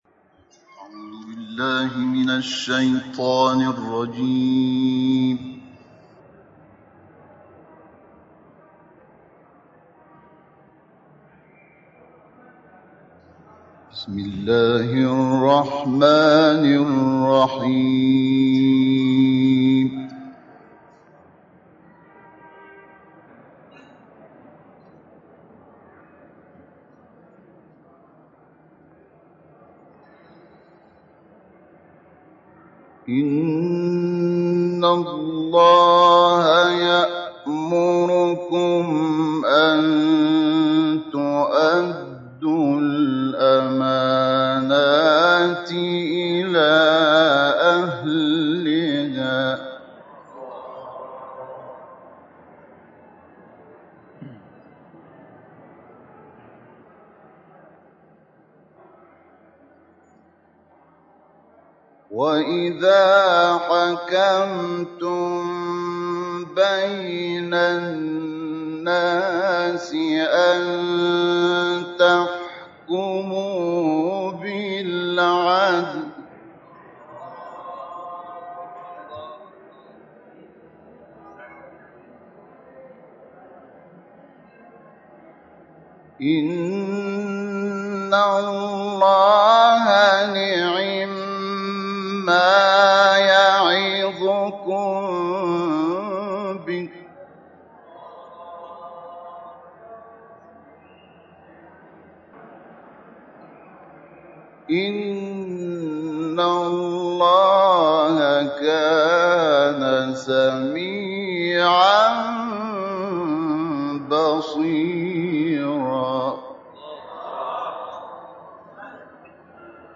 تلاوت آیاتی از سوره نساء توسط مرحوم شحات محمد انور